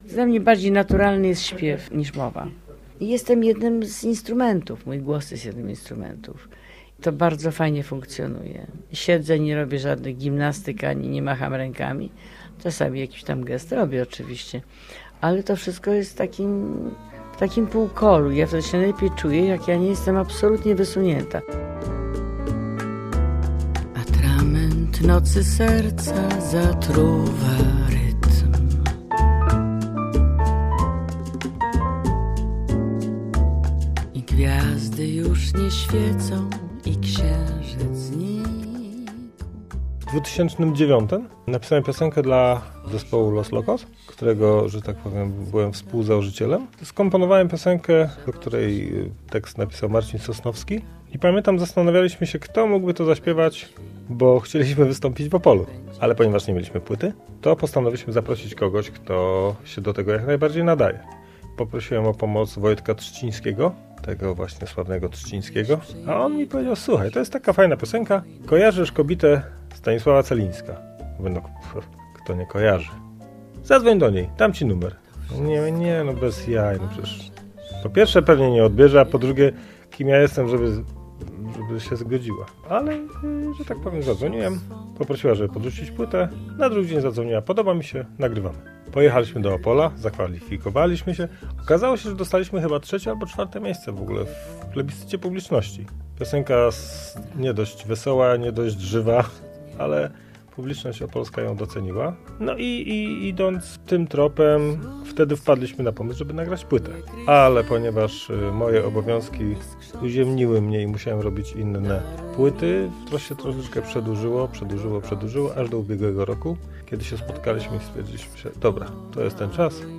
Amarantowa próba - reportaż